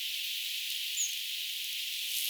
Tiainen äänteli siinä paikassa kuin kirvinen?
Vai onko tuo ihan oikea kirvinen?
tiainen ääntelee kuin kirvinen?
onko_tuo_tiaisen_kuin_jokin_kirvismatkinta_vai_onko_se_oikea_kirvinen.mp3